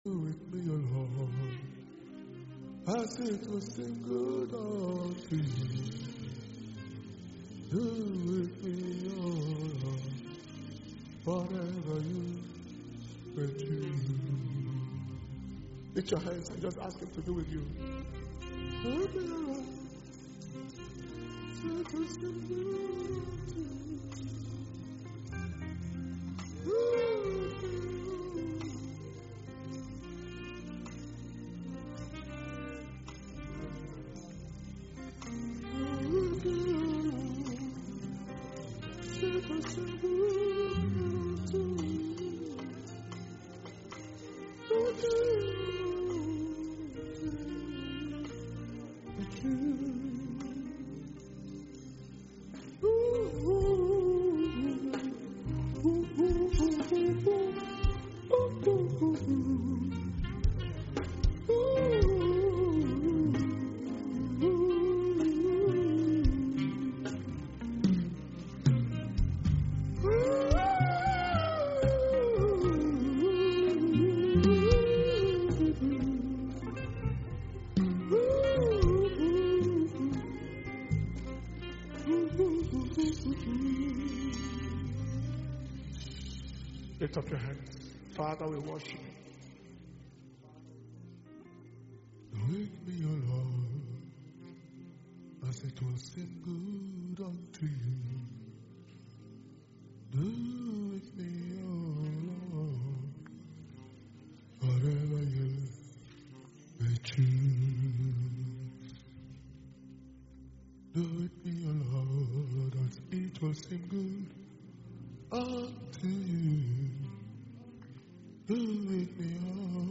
8th September 2021 Message
Power Communion Service